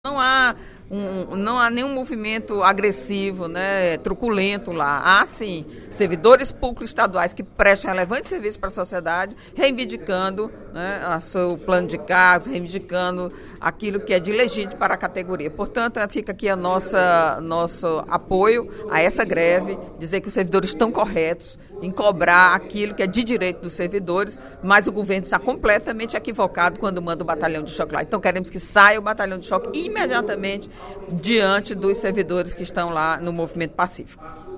Na sessão plenária desta quinta-feira (07/11), a deputada Eliane Novais (PSB) condenou a presença do Batalhão de Choque nas manifestações dos servidores do Departamento Estadual de Trânsito do Ceará (Detran-CE), que se encontram em estado de greve desde o dia 17 de outubro.